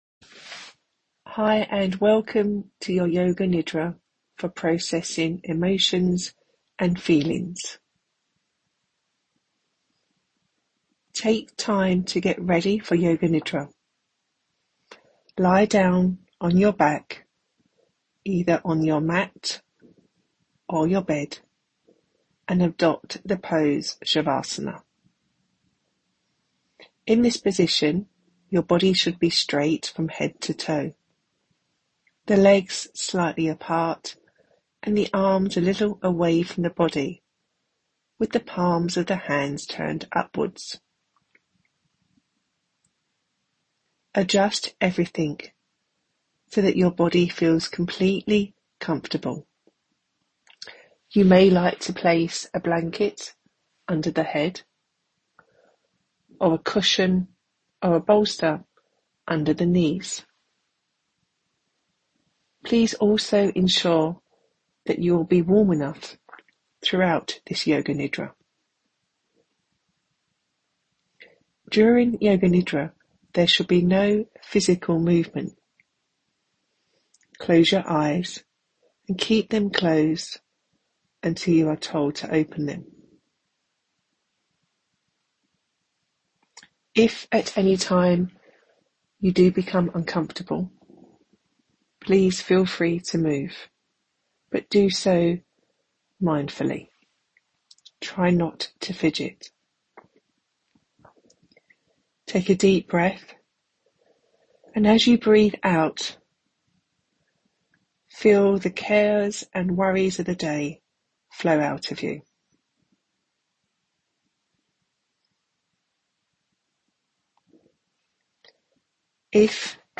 Yoga Nidra, also known as "yogic sleep," is a deeply restorative guided meditation technique that helps individuals enter a state of conscious relaxation.
Yoga_Nidra-Healing_within_Yoga-1.mp3